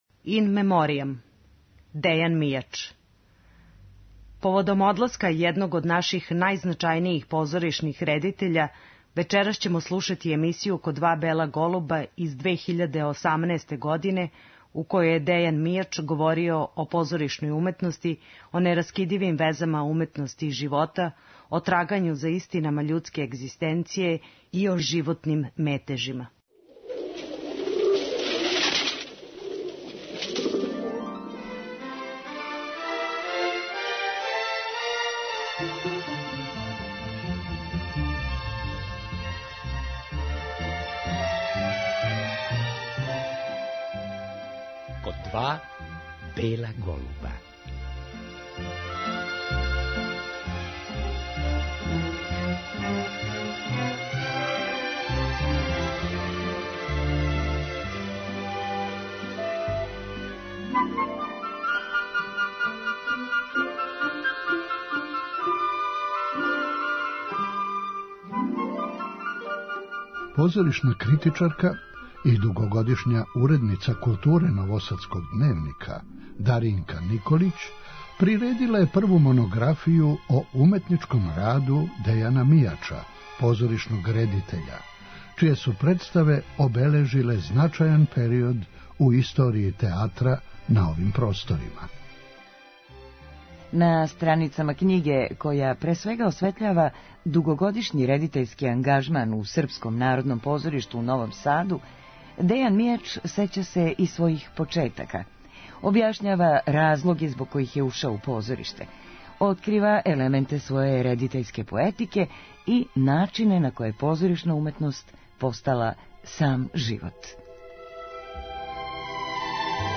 Поводом одласка једног од најзначајнијих позоришних редитеља југословенског и српског театра, вечерас ћемо слушати емисију у којој је Дејан Мијач говорио о позоришној уметности, о тајнама режије и Београду који је заволео још у студентским данима.